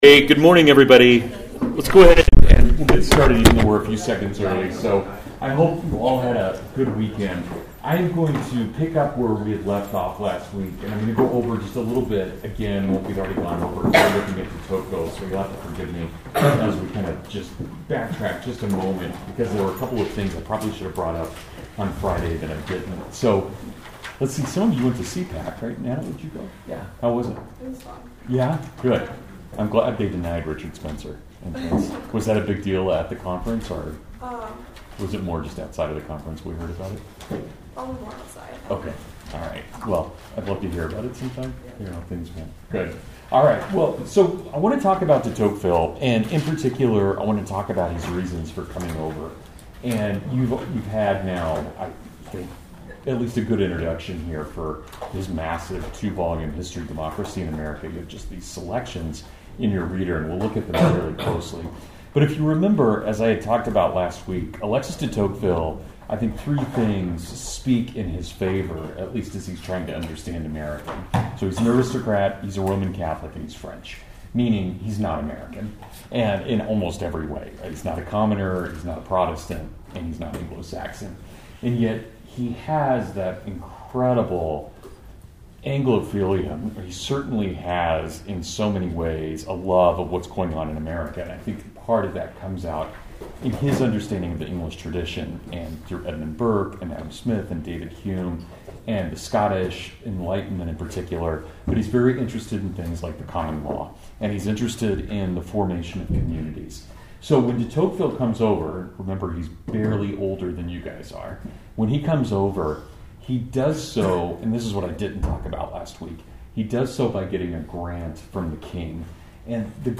Democracy in America, Part I (Full Lecture)